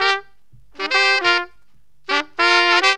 HORN RIFF 15.wav